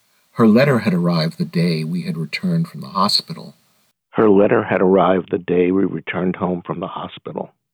Today I went in with, I thought, the exact same settings and setup and the quality is noticeably worse.
Here is a sample of the good, then the not so good.
The second version is highly processed (AI?)